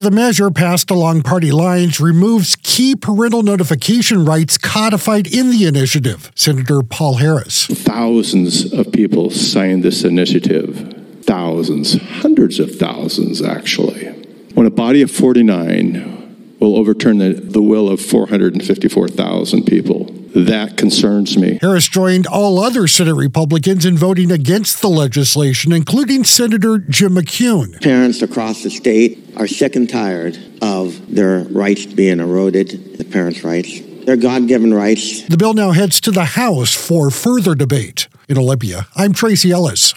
AUDIO: Republicans oppose Senate passage of bill to undermine parental-rights initiative - Senate Republican Caucus